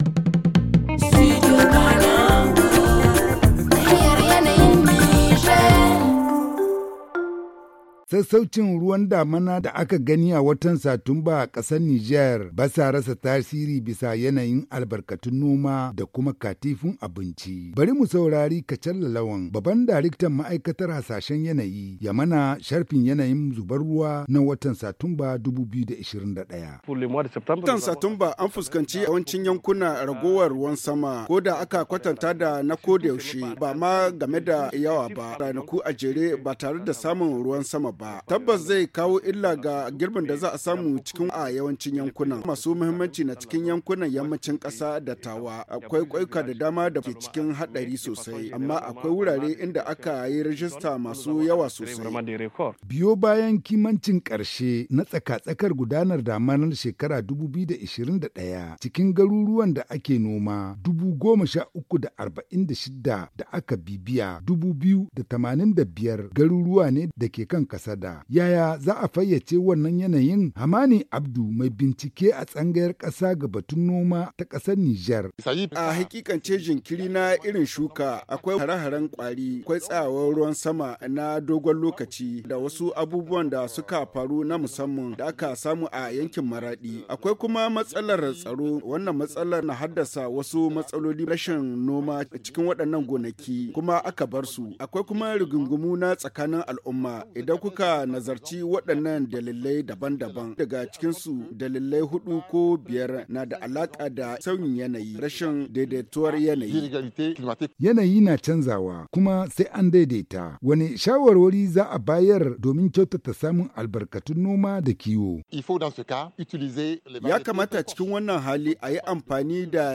Les experts du cadre national pour les services climatiques expliquent la situation